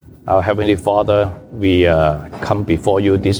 西堂證道(粵語/國語) Sunday Service Chinese: 有果效的事奉